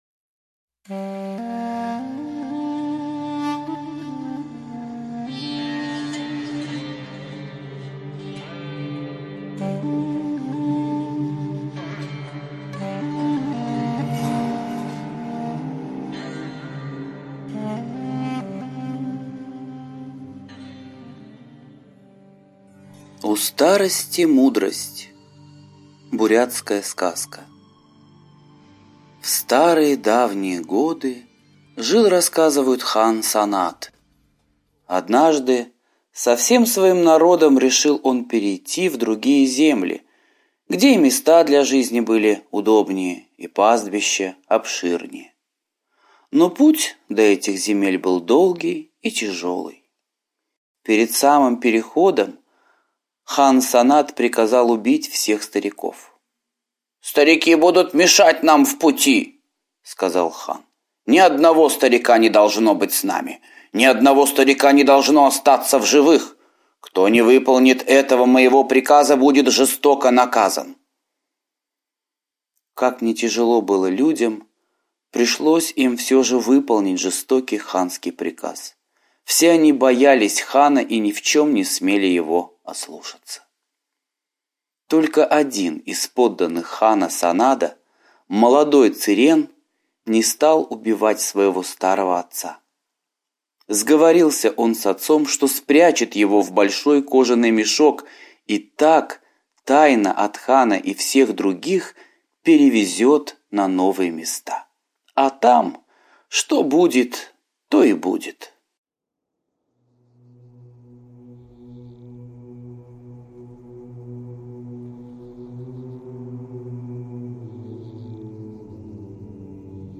У старости-мудрость - восточная аудиосказка - слушать онлайн